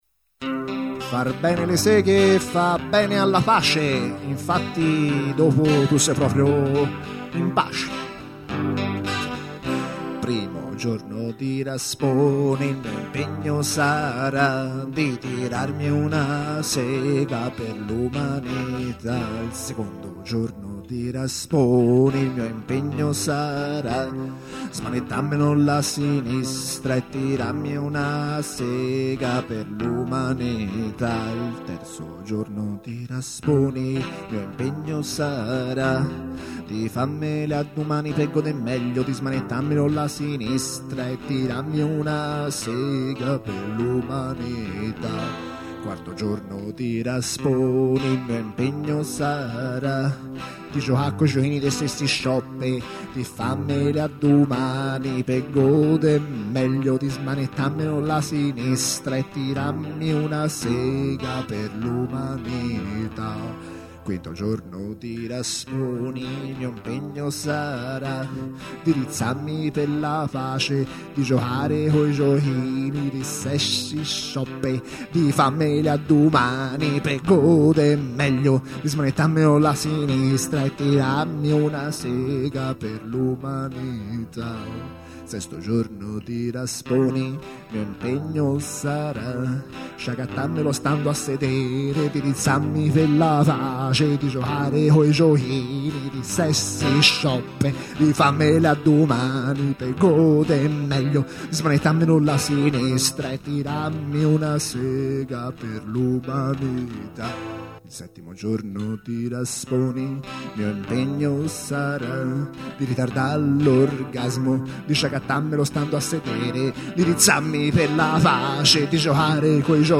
cover
e l'ho sostituita con una di Branduardiana memoria.